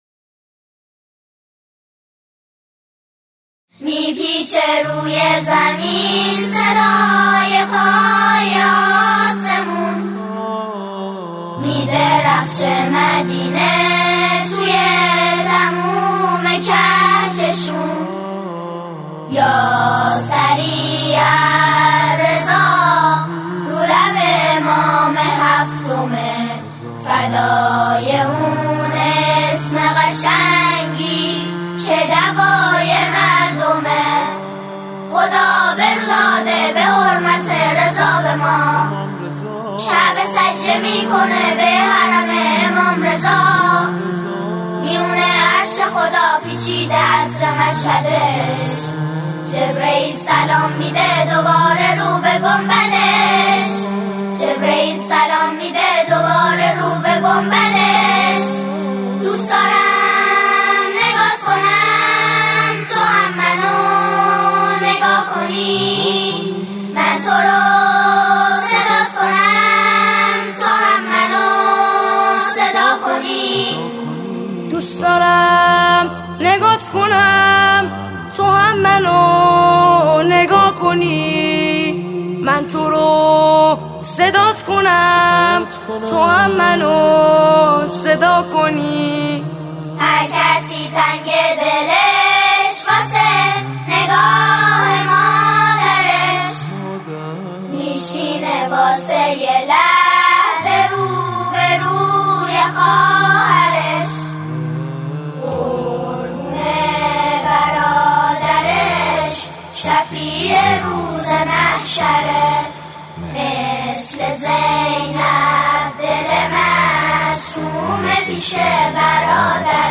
سرودهای امام رضا علیه السلام